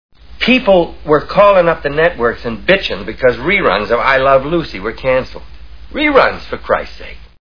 Capricorn One Movie Sound Bites